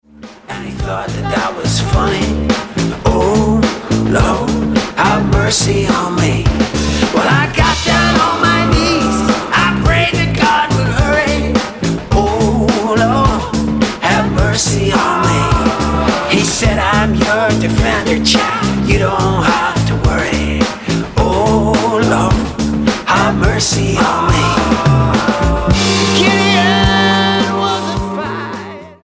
STYLE: Blues
satisfyingly raw collection of original gospel blues numbers